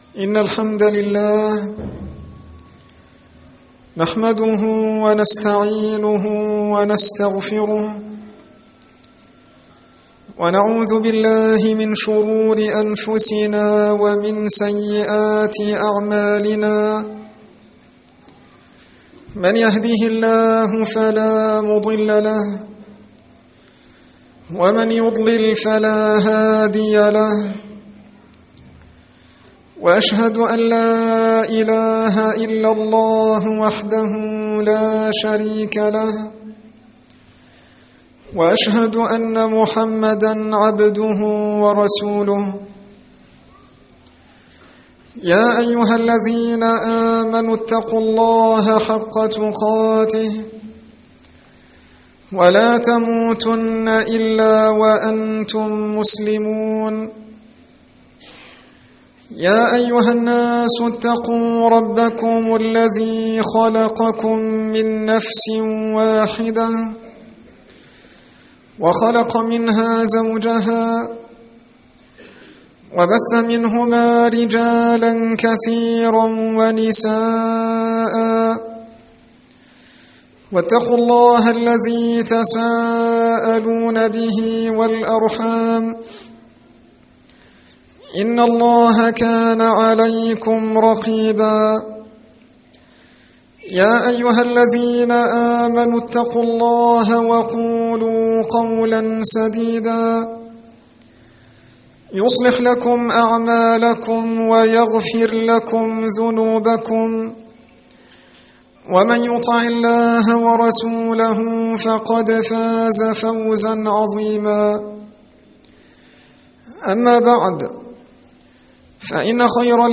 الخـطب